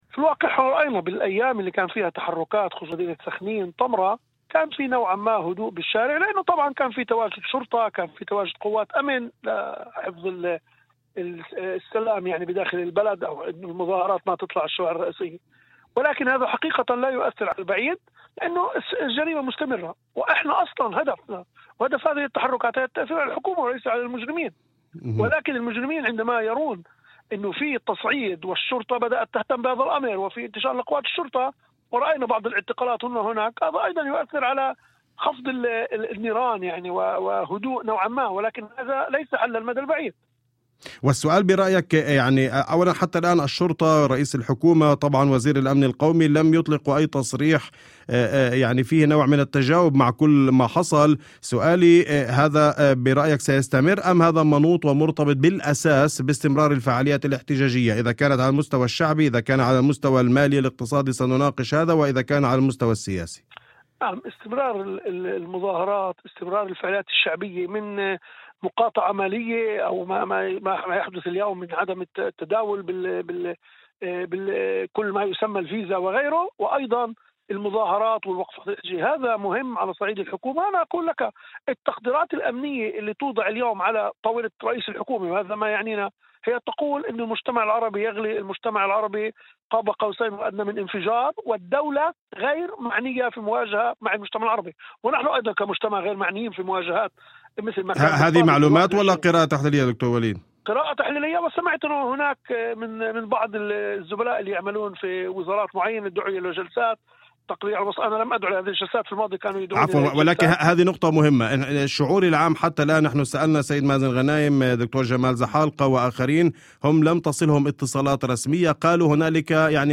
وأضاف في مداخلة هاتفية لبرنامج "أول خبر"، على إذاعة الشمس، أن هدف التحركات الشعبية لا يتركز على المجرمين أنفسهم بل على الحكومة.